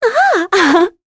Talisha-Vox_Happy5.wav